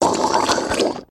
Mud Suck Gurgle